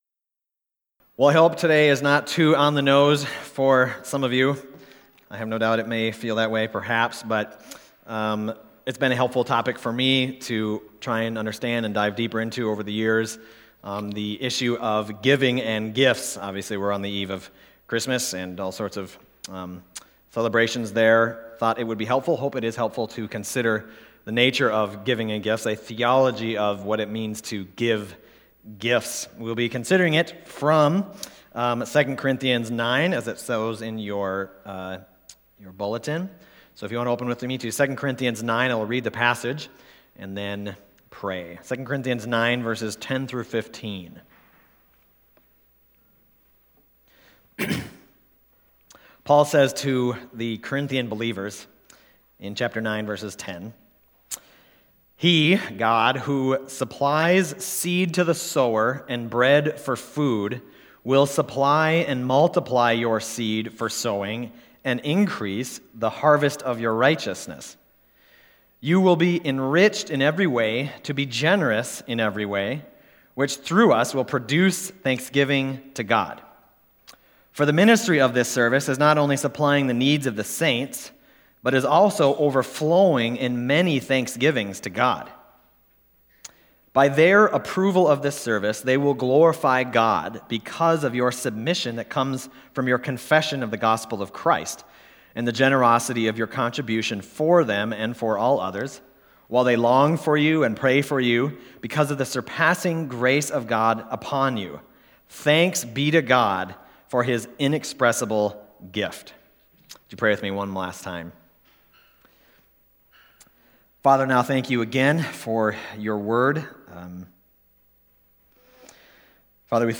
Passage: 2 Corinthians 9:10-15 Service Type: Sunday Morning